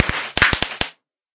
twinkle1.ogg